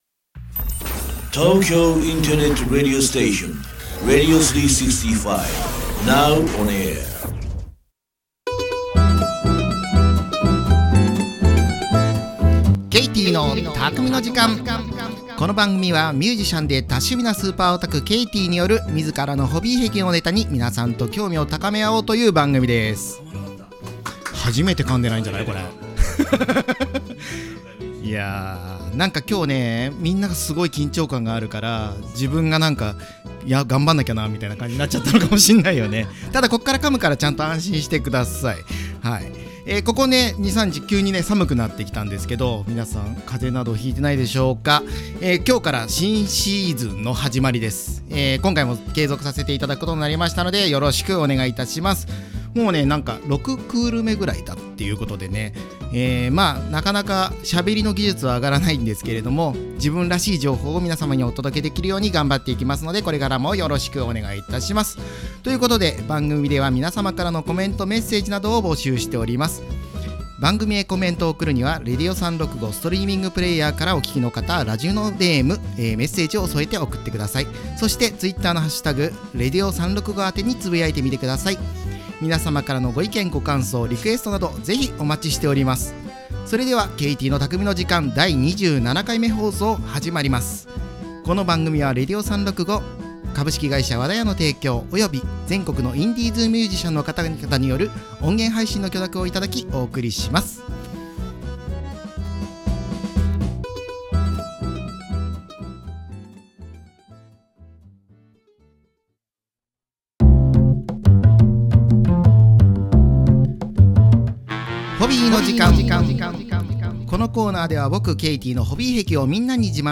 【この音源は生放送のアーカイブ音源となります】